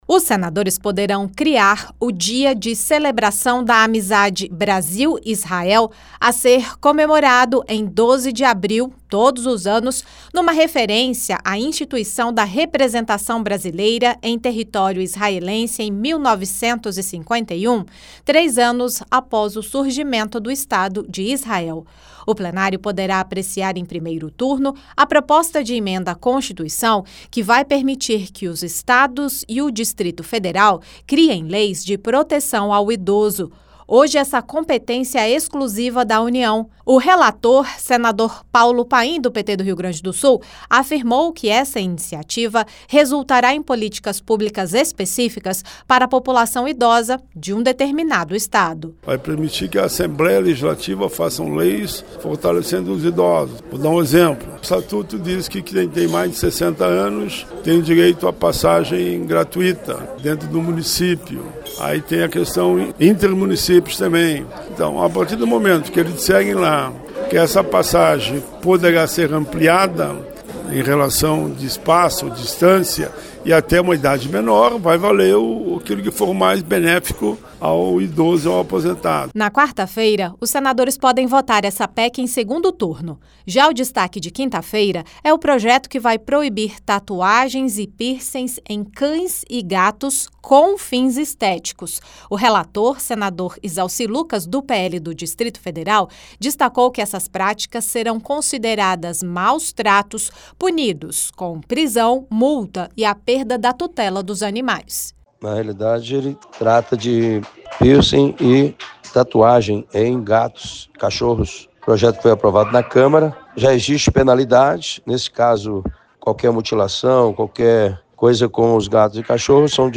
Ao lembrar que hoje essa competência é exclusiva da União, o relator, senador Paulo Paim (PT-RS), afirmou que a iniciativa resultará em políticas públicas específicas para a população idosa. Na quinta-feira (15), os senadores deverão votar a proibição de tatuagens e piercings em cães e gatos com fins estéticos. O relator, senador Izalci Lucas (PL-DF), argumentou que essas práticas serão consideradas maus-tratos contra animais.